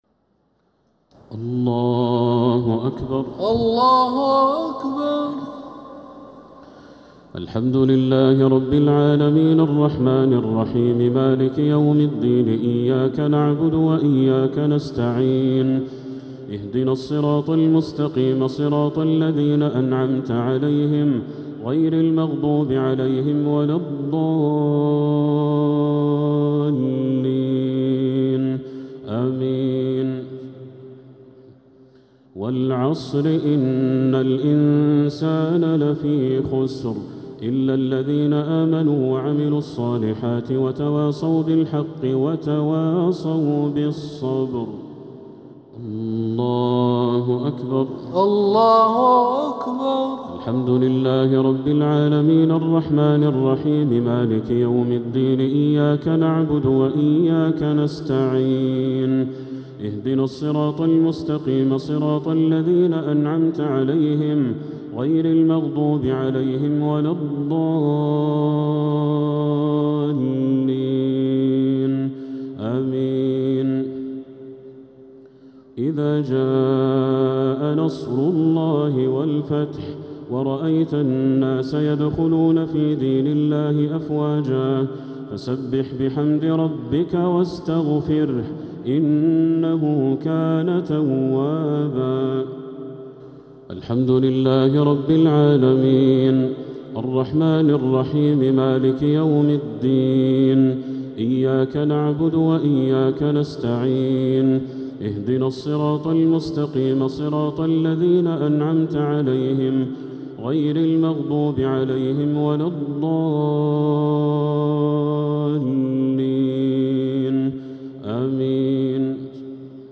الشفع و الوتر ليلة 11رمضان 1447هـ | Witr 11th night Ramadan 1447H > تراويح الحرم المكي عام 1447 🕋 > التراويح - تلاوات الحرمين